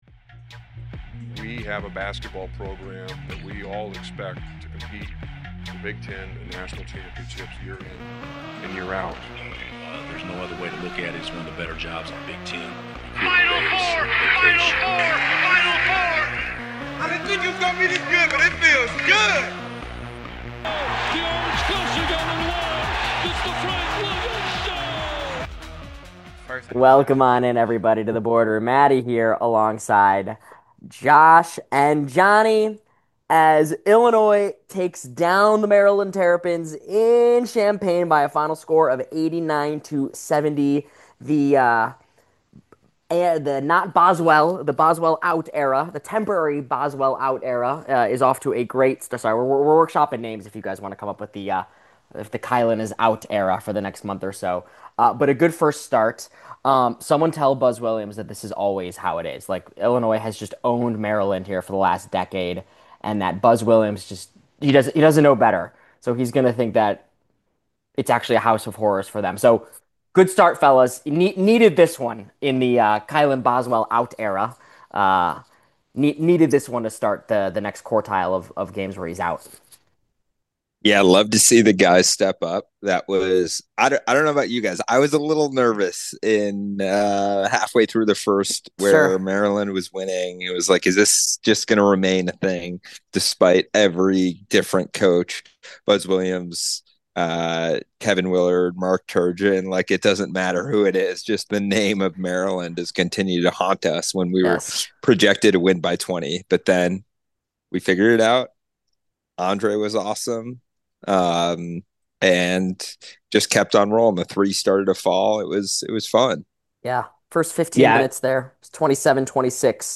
Down goes mighty Maryland (At least it's felt that way for Illinois the last decade). The fellas discuss.